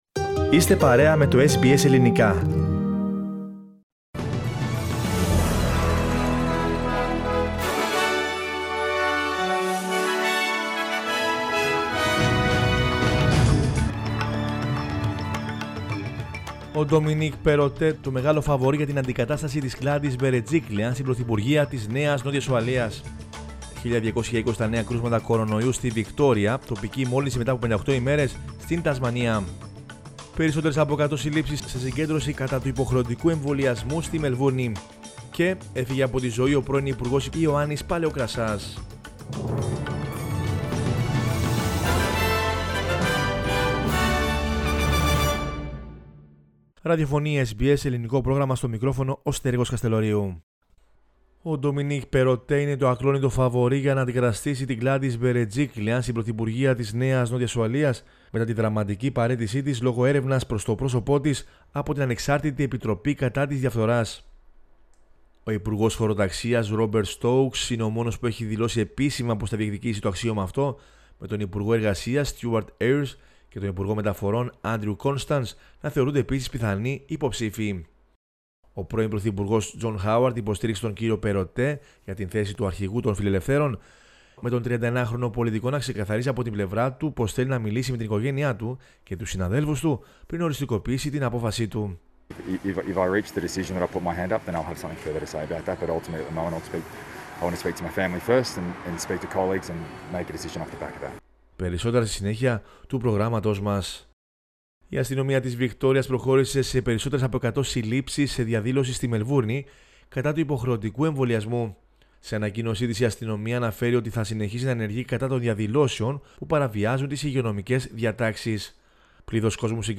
News in Greek from Australia, Greece, Cyprus and the world is the news bulletin of Sunday 3 October 2021.